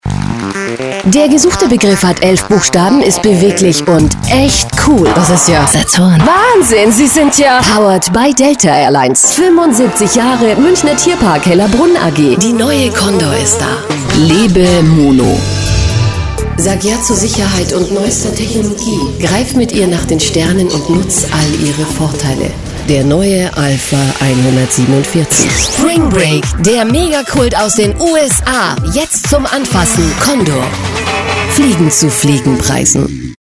Kein Dialekt
Sprechprobe: Werbung (Muttersprache):
german female voice over artist.